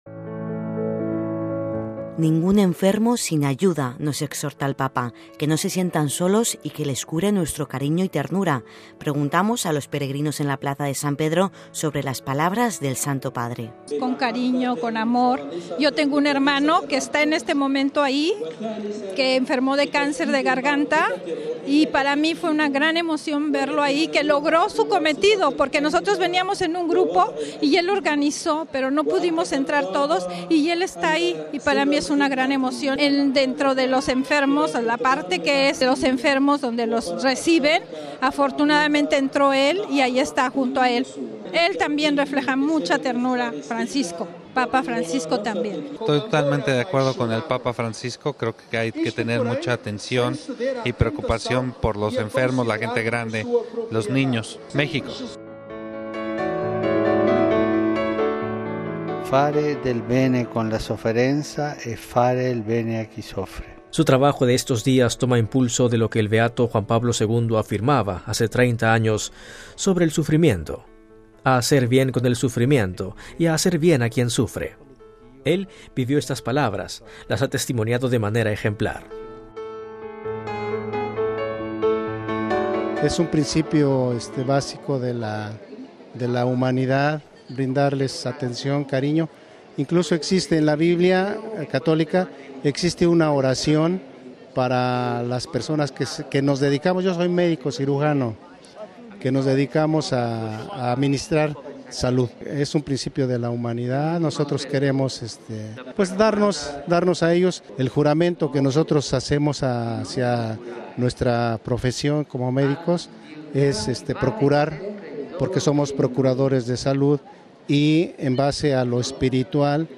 Preguntamos a los peregrinos llegados a la plaza de San Pedro sobre las palabras del Santo Padre.